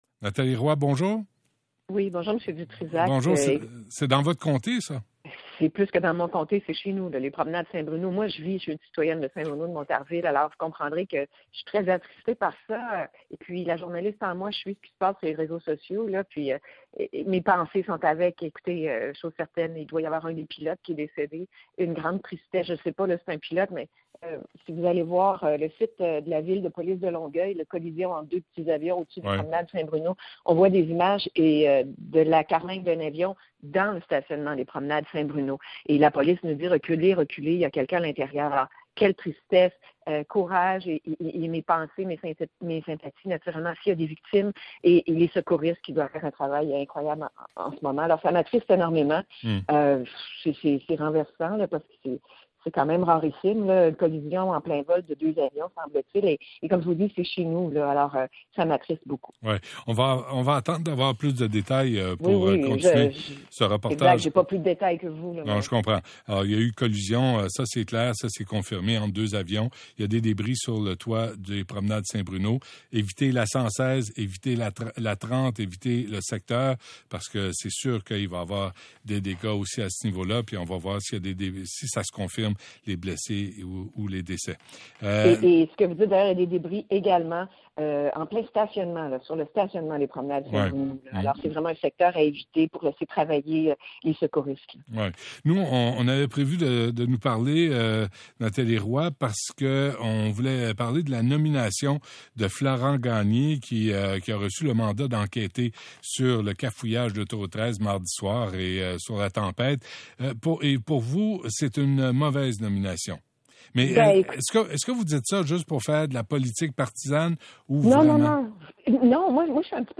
Nomination de Florent Gagné: Nathalie Roy en entrevue avec Benoit Dutrizac du 98.5FM